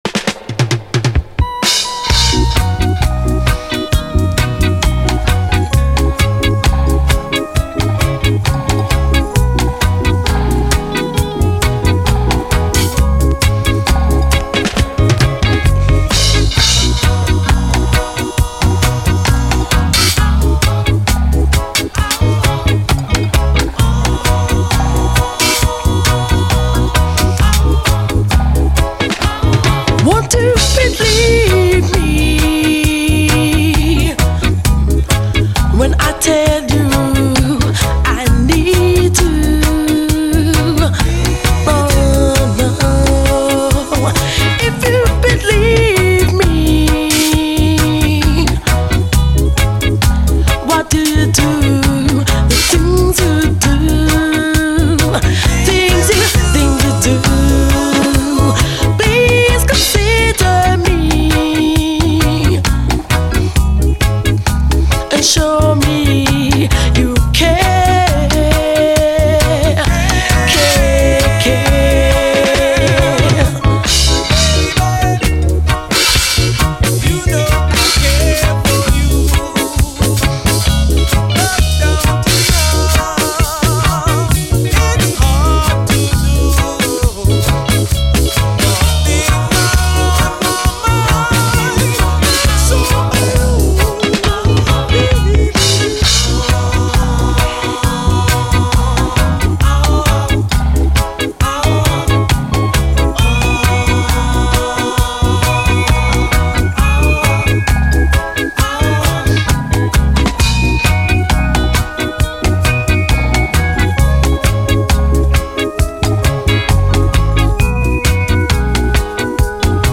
REGGAE
ハスキーな女性ヴォーカルがイイ！
ハスキーでカッコいい歌声が特徴的な女性ヴォーカルを中心に、途中の男性ヴォーカルも熱いステッパー・チューン！